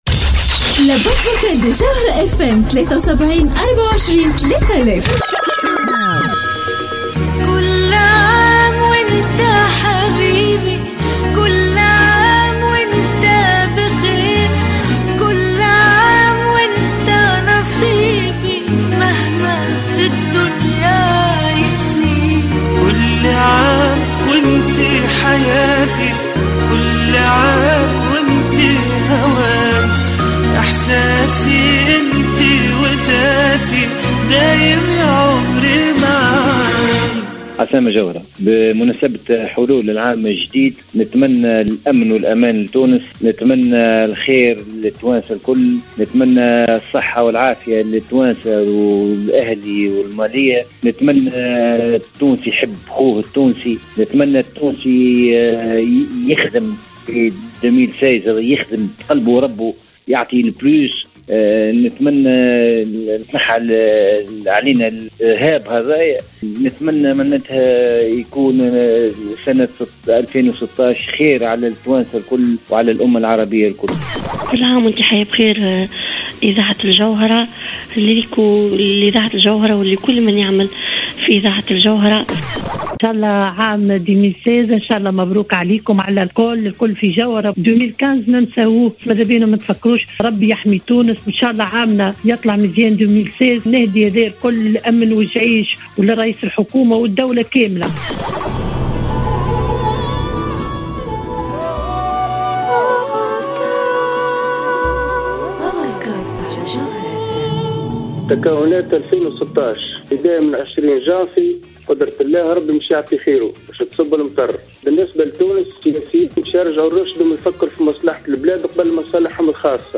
Les vœux des auditeurs de Jawhara Fm pour l'année 2016